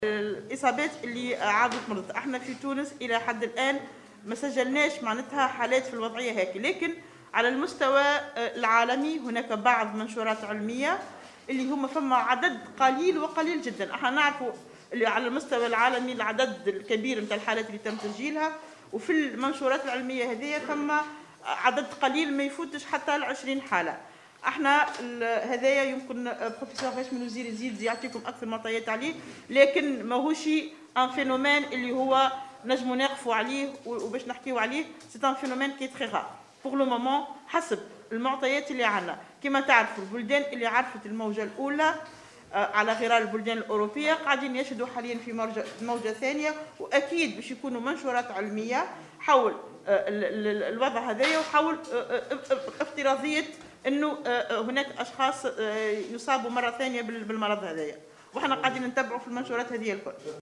وأوضحت في ردّها على سؤال لمبعوث "الجوهرة أف أم" أنه على المستوى العالمي تبقى نسبة الإصابة بالفيروس للمرة الثانية ضئيلة (لا تتجاوز 20 إصابة إلى حدّ الآن)، وفق ما أوردته بعض المنشورات العلمية.